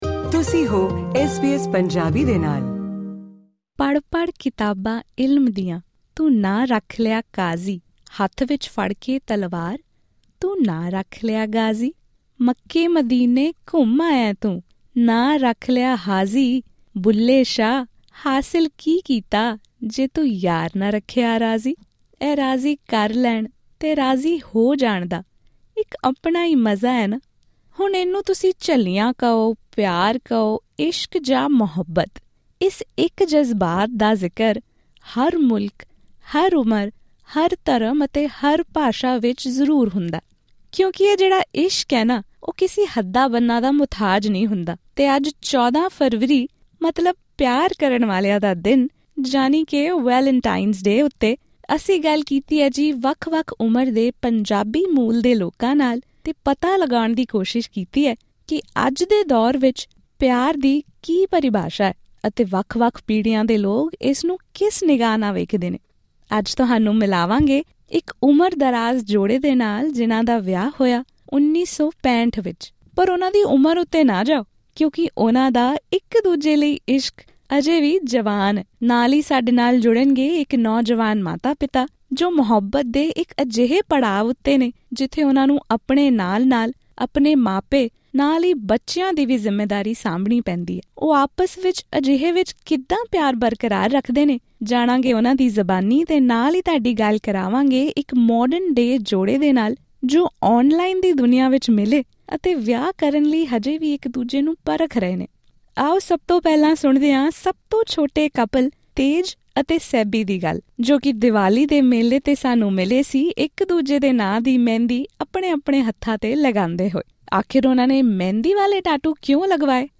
ਆਓ ਆਸਟ੍ਰੇਲੀਆ ਵੱਸਦੇ ਵੱਖੋ ਵੱਖ ਉਮਰ ਵਰਗ ਦੇ ਜੋੜਿਆਂ ਤੋਂ ਜਾਣੀਏ ਉਨ੍ਹਾਂ ਦੀਆਂ ਕਹਾਣੀਆਂ।